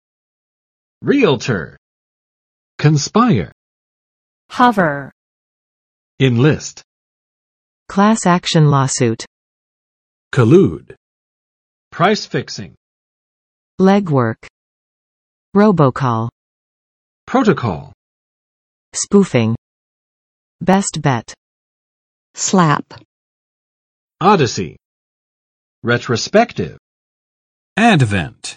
Vocabulary Test - May 16, 2019
[ˋriəltɚ] n.【美】（亦作R-）房地产经纪人
realtor.mp3